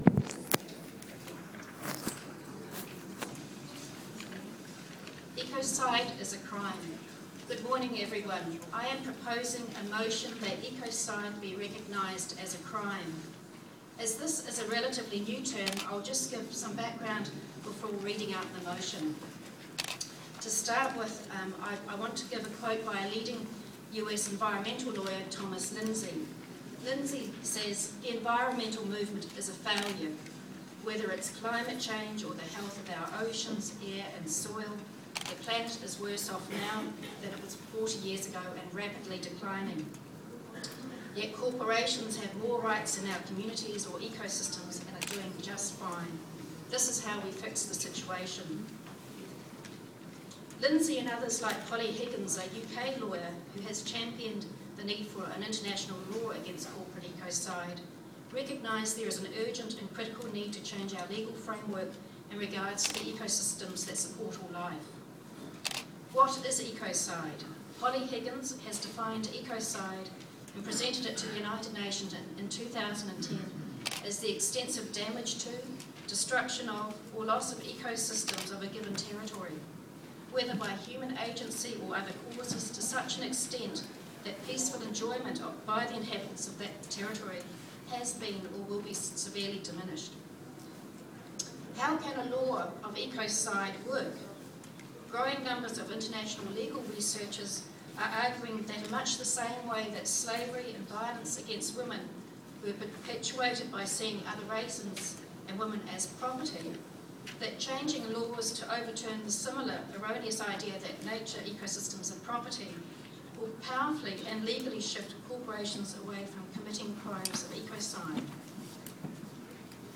Listen to my 2013 talk to propose ‘Ecocide is a Crime’ at the Green Party of Ireland and Northern Ireland, 13 April below: